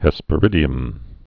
(hĕspə-rĭdē-əm)